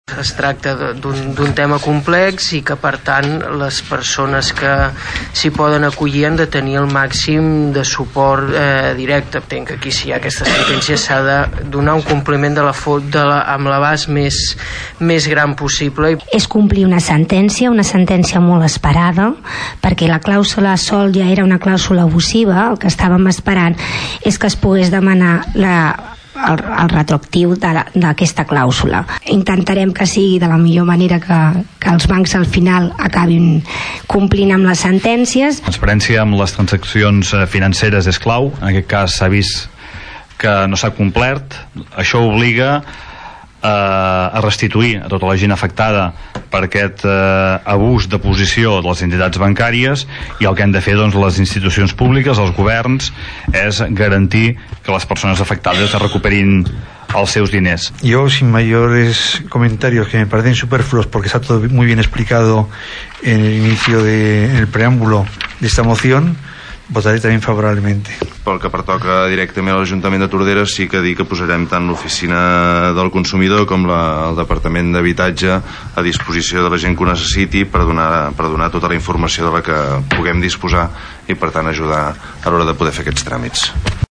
Els partits polítics es van posicionar a favor de la moció. Escoltem Xavier Martin del PP, Toñi Garcia del PSC, Xavier Pla d’ ERC+Gent Tordera, Jose Carlos Villaro (no adscrit) i Josep Llorens del PDECat.